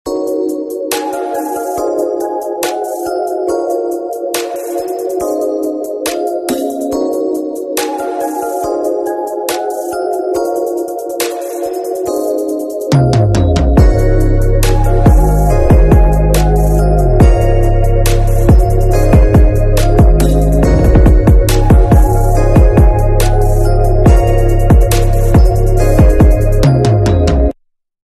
Crystal resonator pure tone. sound effects free download
Mp3 Sound Effect Crystal resonator - pure tone. It’s said to have the following effects: cleanses and balances your energy, charges quartz crystals and crystals in the brain, activates aspects of the aura. Headphones recommended but not necessary.